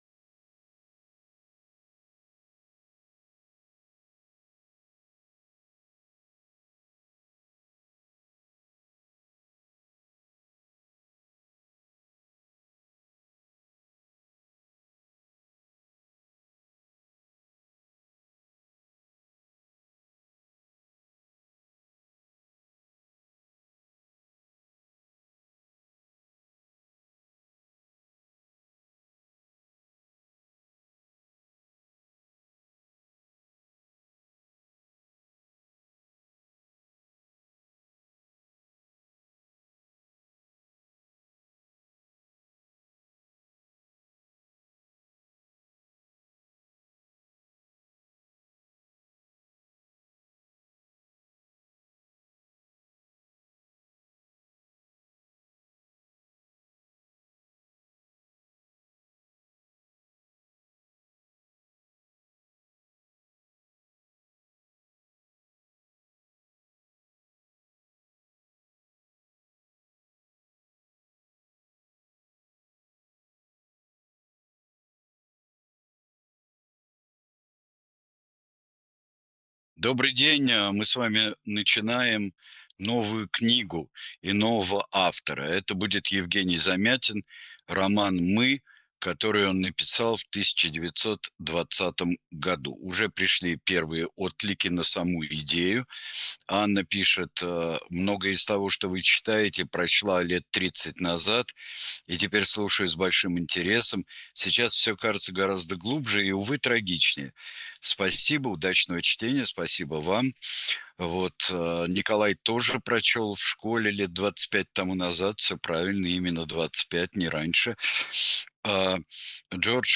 Читает Сергей Бунтман.